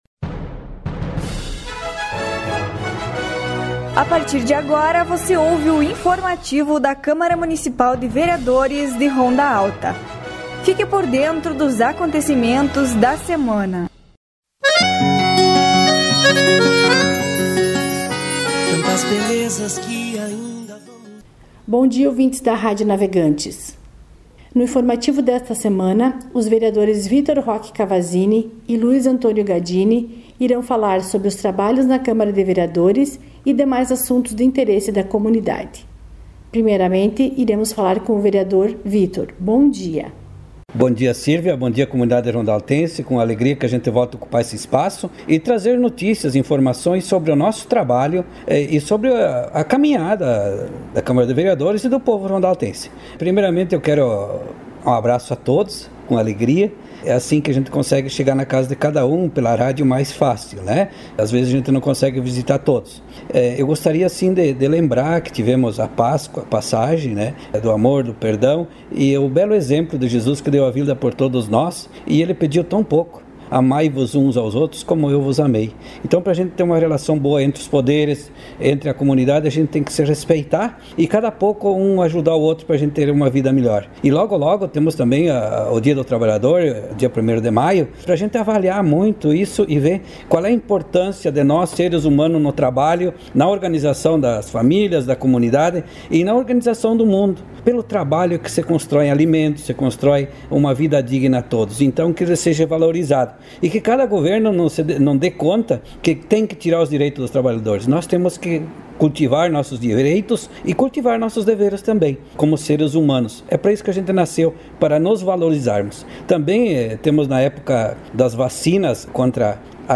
Informativos radiofônicos